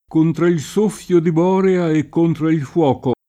k1ntra il S1ffLo di b0rea e kk1ntra il fU0ko] (Ariosto) — con B‑ maiusc. come pers. m. mit.